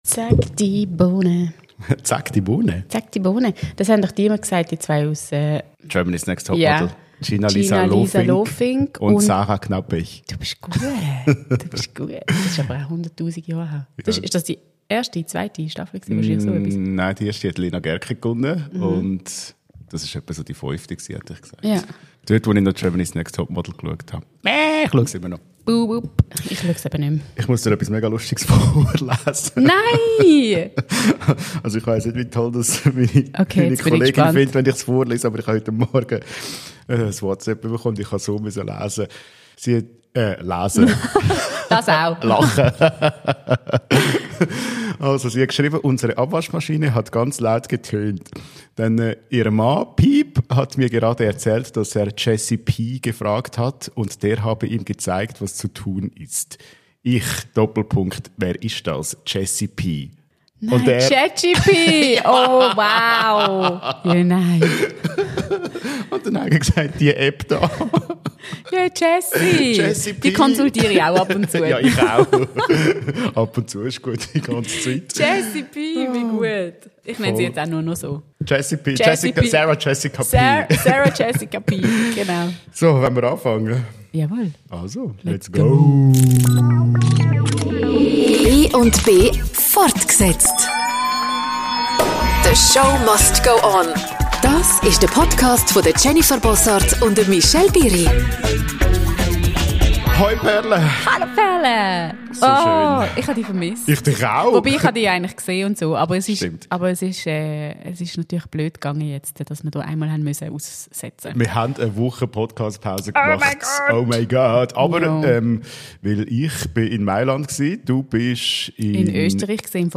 Beschreibung vor 5 Monaten Nach einer Woche Pause (SORRY!) sind eure Moderationsdüsen wieder zurück am Mikro – und es gibt einiges aufzuarbeiten.